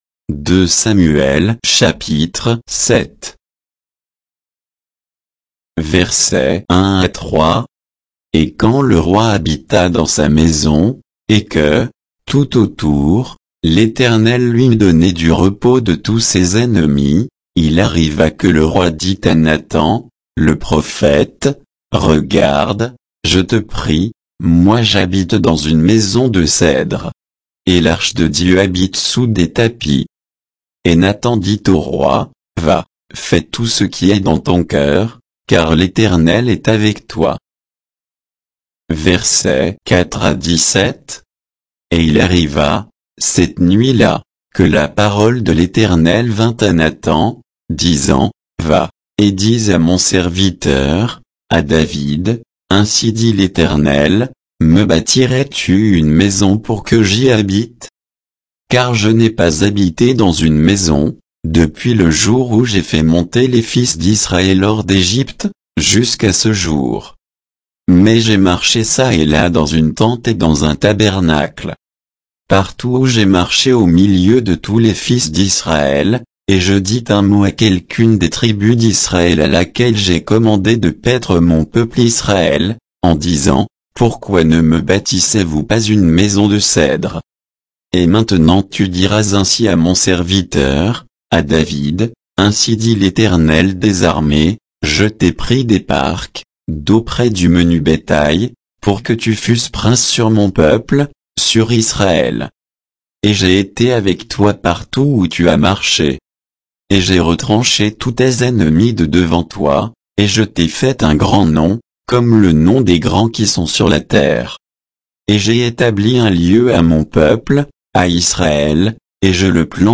Bible_2_Samuel_7_(avec_notes_et_indications_de_versets).mp3